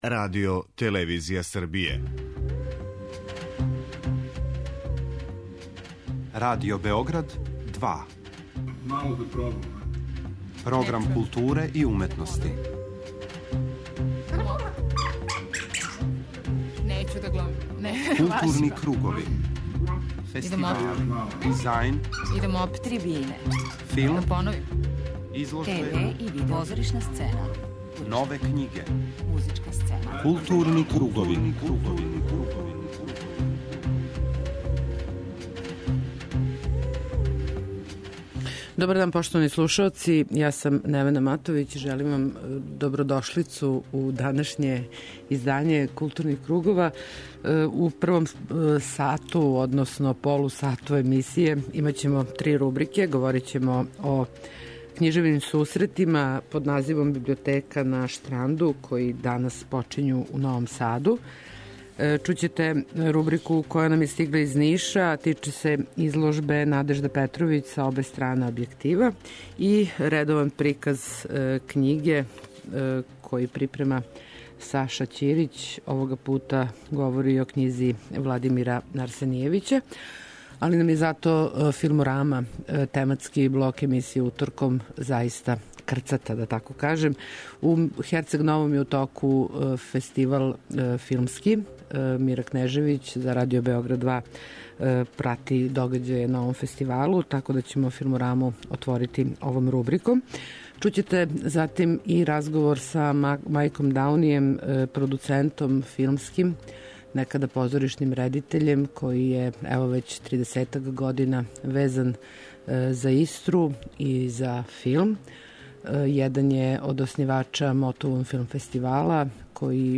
преузми : 53.88 MB Културни кругови Autor: Група аутора Централна културно-уметничка емисија Радио Београда 2.